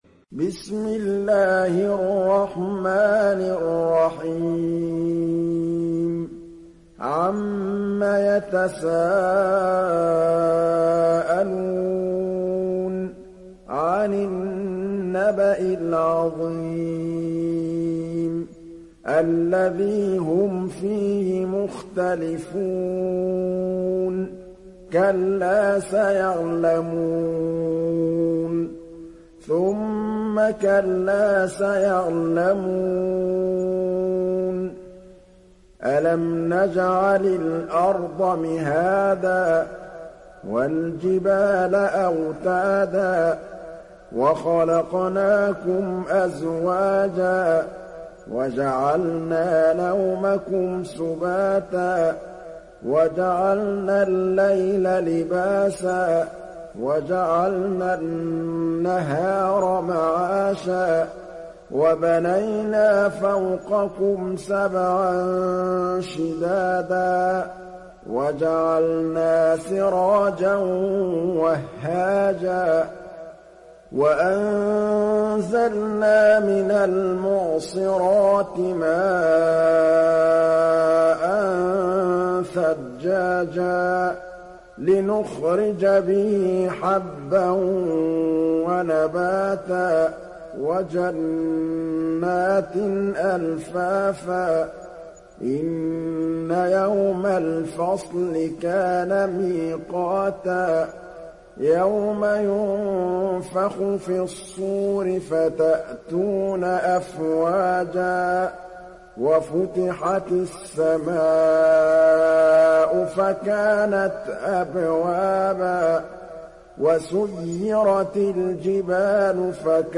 تحميل سورة النبأ mp3 بصوت محمد محمود الطبلاوي برواية حفص عن عاصم, تحميل استماع القرآن الكريم على الجوال mp3 كاملا بروابط مباشرة وسريعة